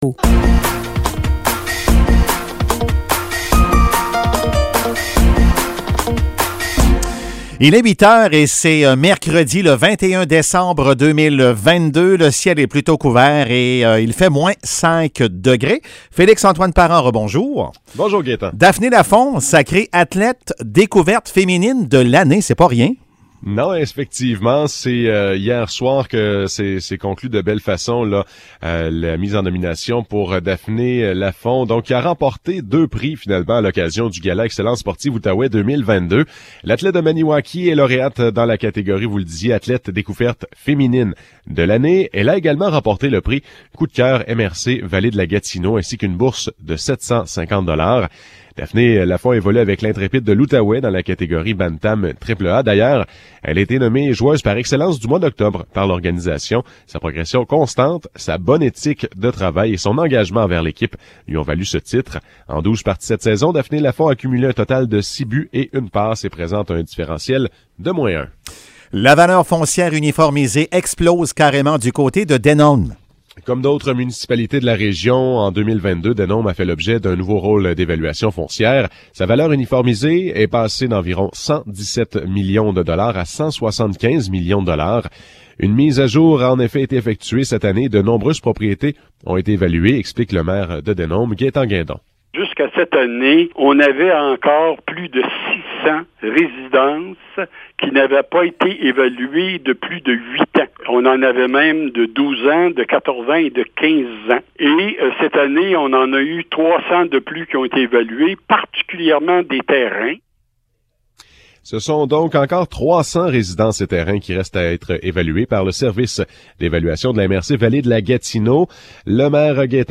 Nouvelles locales - 21 décembre 2022 - 8 h